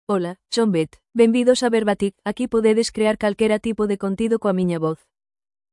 Beth — Female Galician (Spain) AI Voice | TTS, Voice Cloning & Video | Verbatik AI
FemaleGalician (Spain)
Voice sample
Female
Beth delivers clear pronunciation with authentic Spain Galician intonation, making your content sound professionally produced.